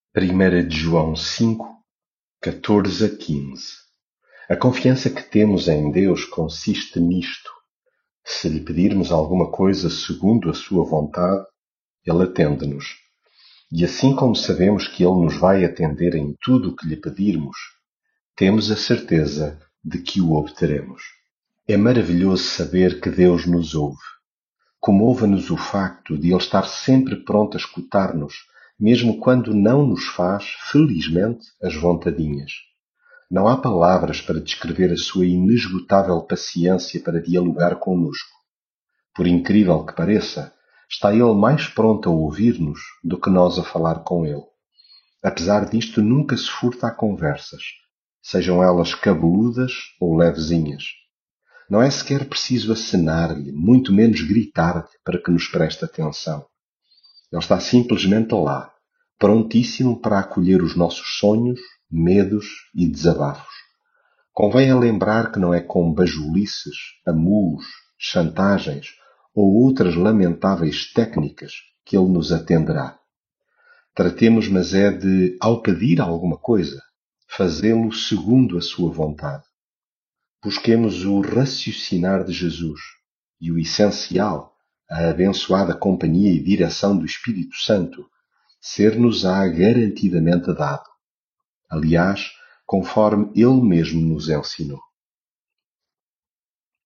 leitura bíblica